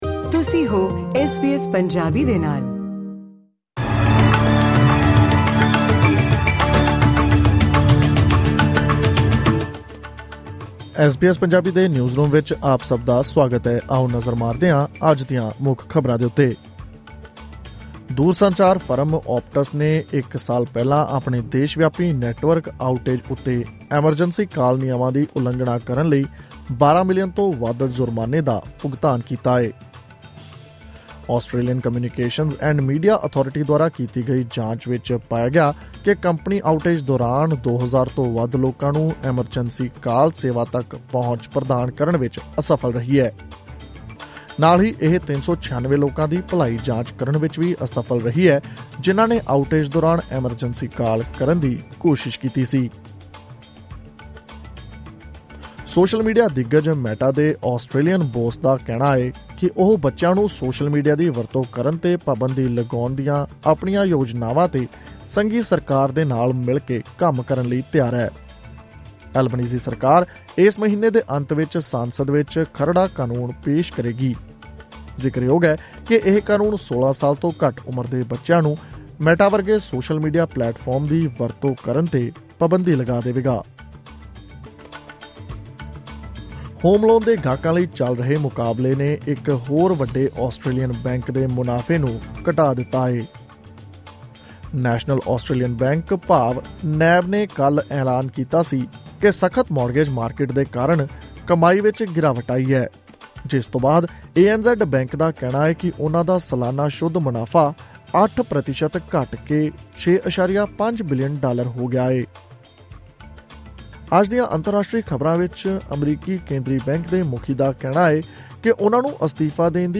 ਐਸ ਬੀ ਐਸ ਪੰਜਾਬੀ ਤੋਂ ਆਸਟ੍ਰੇਲੀਆ ਦੀਆਂ ਮੁੱਖ ਖ਼ਬਰਾਂ: 8 ਨਵੰਬਰ 2024